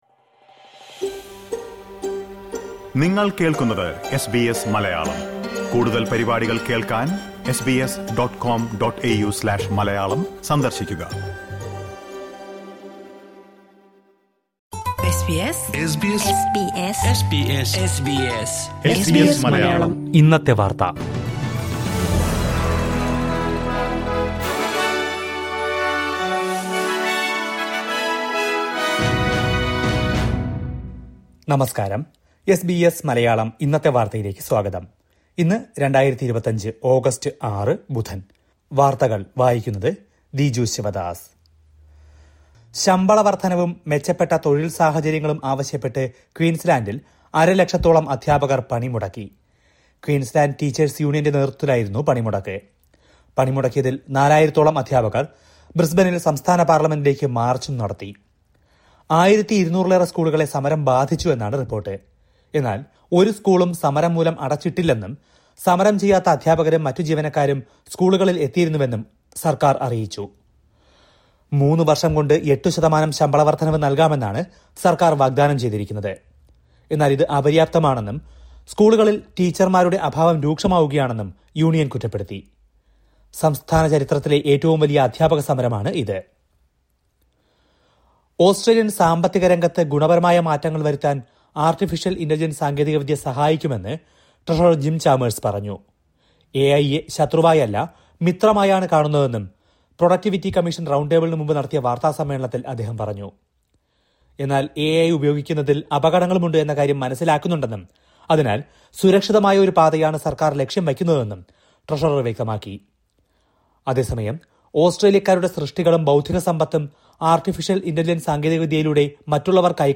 2025 ഓഗസ്റ്റ് ആറിലെ ഓസ്ട്രേലിയയിലെ ഏറ്റവും പ്രധാന വാർത്തകൾ കേൾക്കാം...